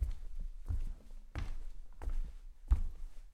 Shagi_k_dveri.ogg